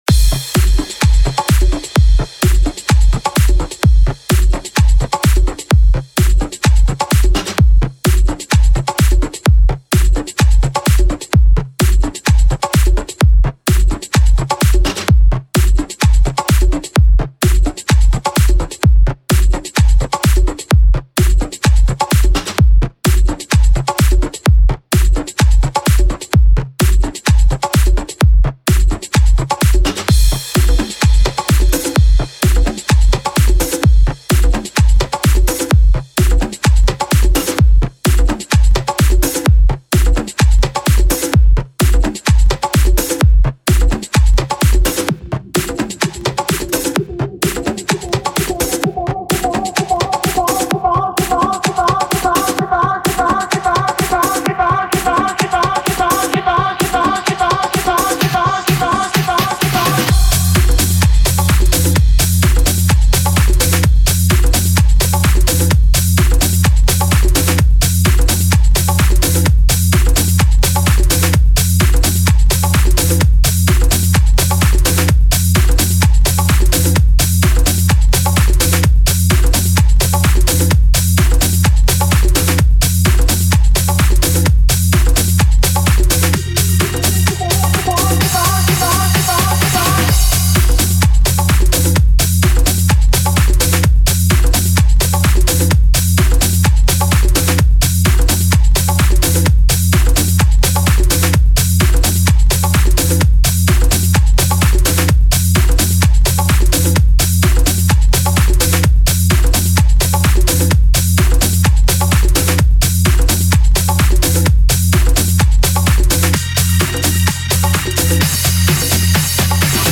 Mixed for broadcast